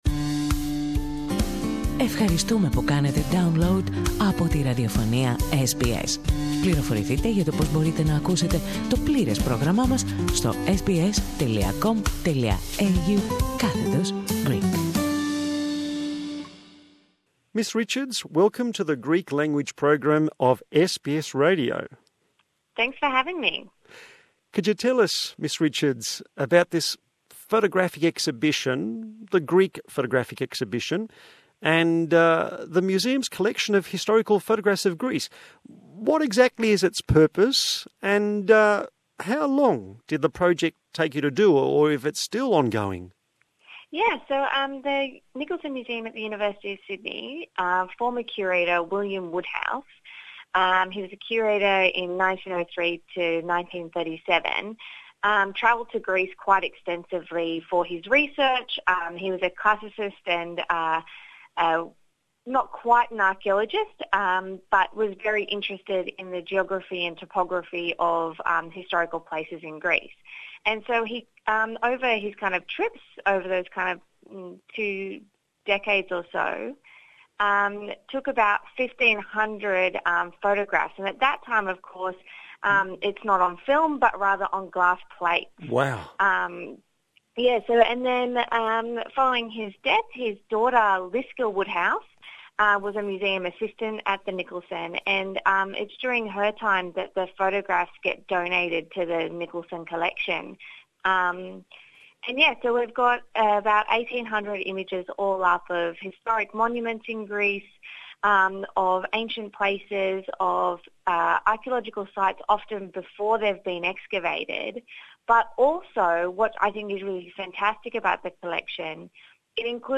The interview is in the English language.